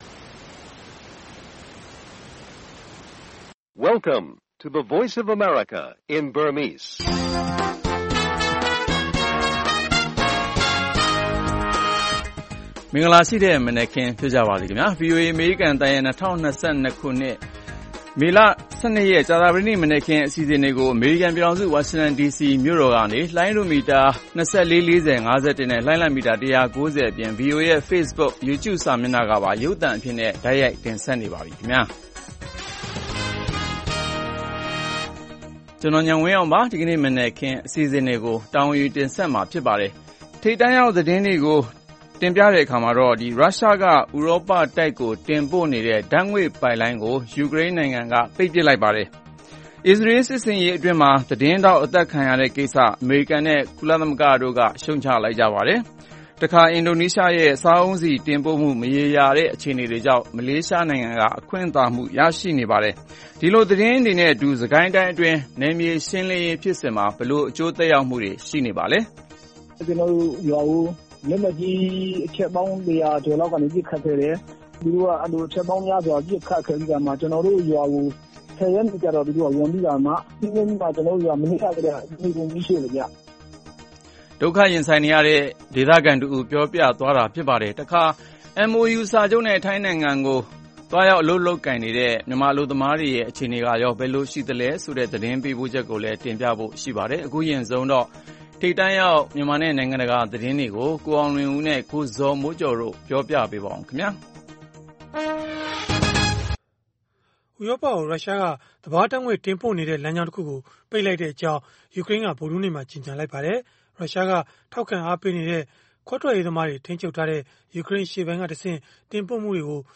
အပြည်ပြည်ဆိုင်ရာ စံတော်ချိန် ၂၃၃၀ ၊ မြန်မာစံတော်ချိန် နံနက် ၆ နာရီကနေ ၇ နာရီထိ (၁) နာရီကြာ ထုတ်လွှင့်နေတဲ့ ဒီ ရေဒီယိုအစီအစဉ်မှာ မြန်မာ၊ ဒေသတွင်းနဲ့ နိုင်ငံတကာ သတင်းနဲ့ သတင်းဆောင်းပါးတွေ သီတင်းပတ်စဉ်ကဏ္ဍတွေကို နားဆင်နိုင်ပါတယ်။